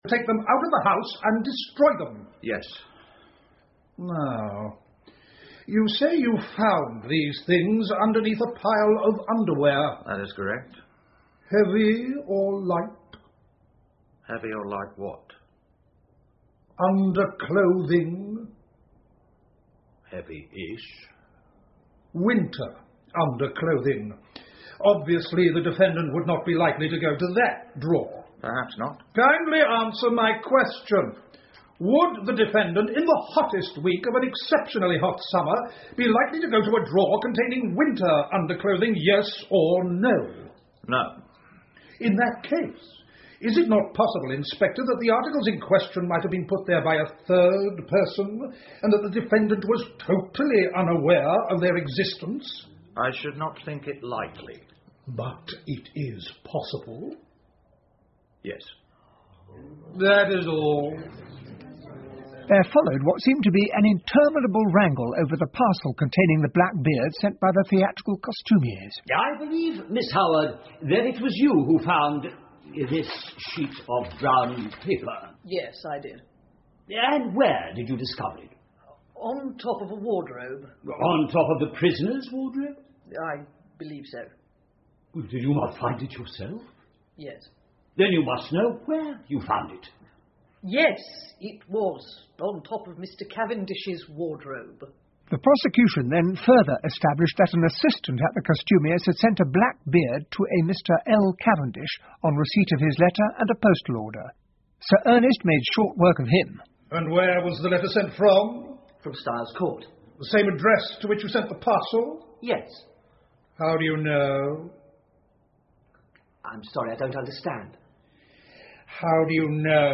英文广播剧在线听 Agatha Christie - Mysterious Affair at Styles 20 听力文件下载—在线英语听力室